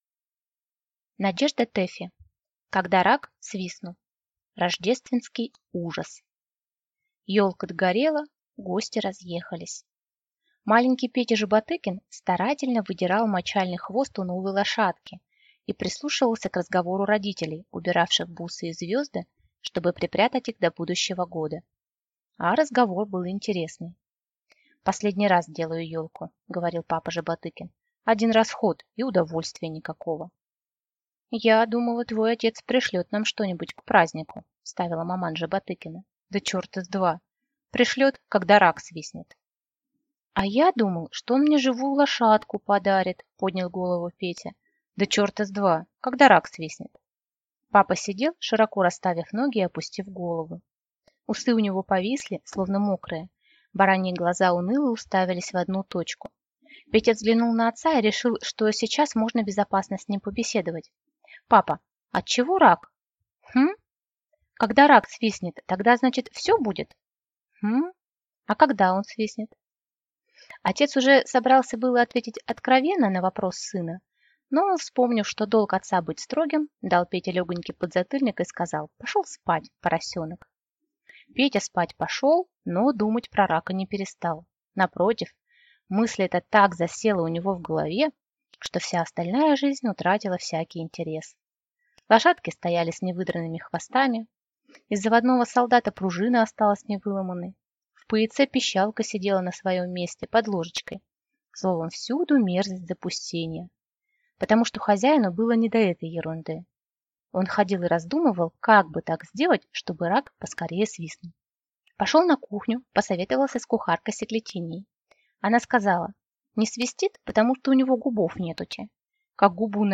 Аудиокнига Когда рак свистнул | Библиотека аудиокниг